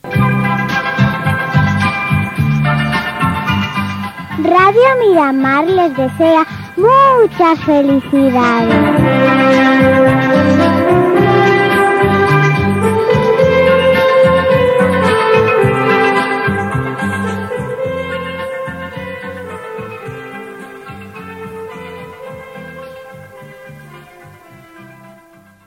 Felicitació de Nadal